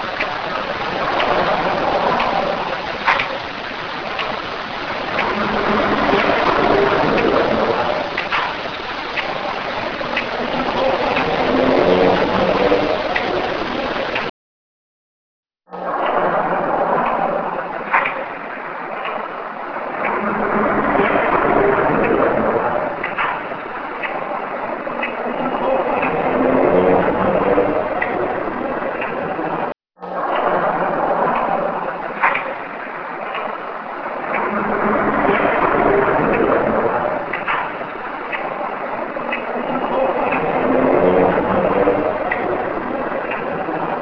Humming - Recorded in the middle of the night, a series of strange sounds picked up by the recorder.
Clip contains original and noise reduction (x2).
Humming.wav